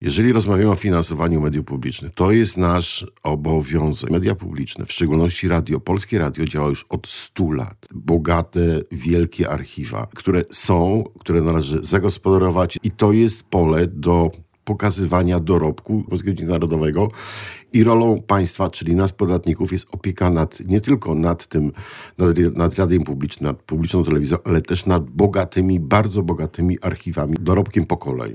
Poseł Piotr Adamowicz, przewodniczący Sejmowej Komisji Kultury i Środków Przekazu, powiedział na antenie Radia Lublin, że założenia nowej ustawy medialnej likwidują Radę Mediów Narodowych oraz wprowadzają transparenty, apolityczny sposób powoływania rad nadzorczych, programowych i władz spółek.